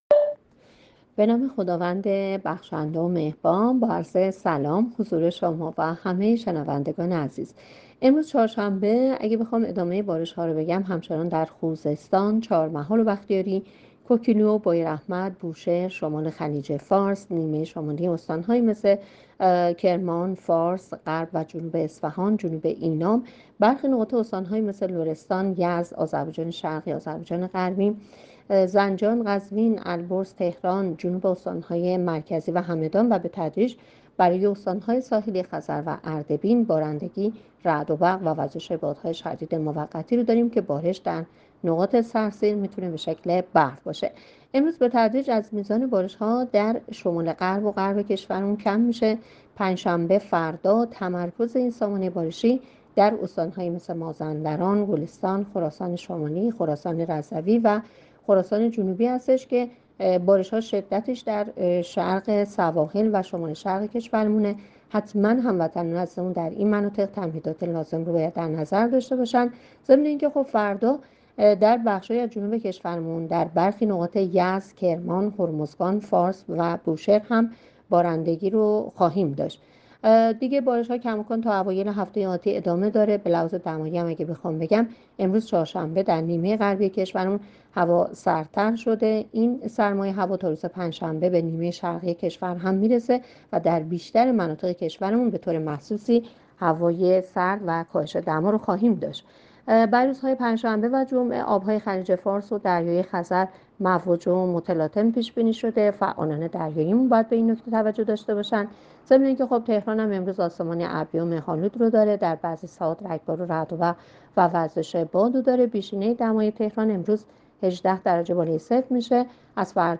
گزارش رادیو اینترنتی پایگاه‌ خبری از آخرین وضعیت آب‌وهوای ۷ آذر؛